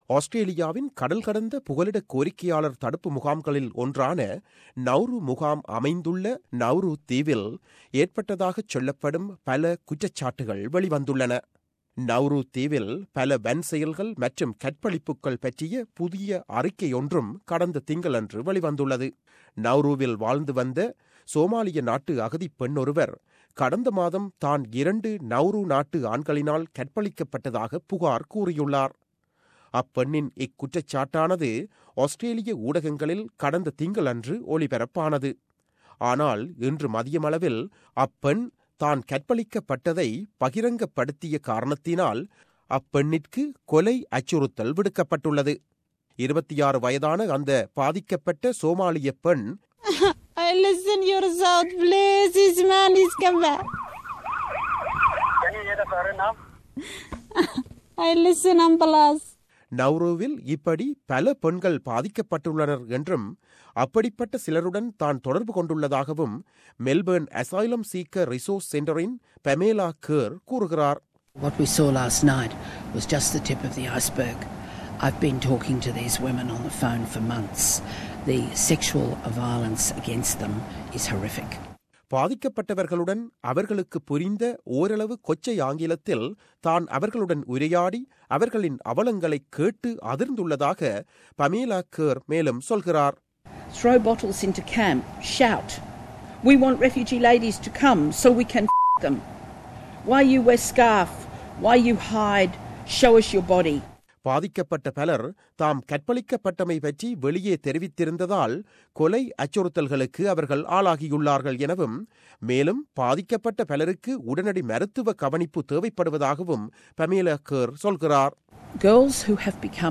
செய்தி விவரணம்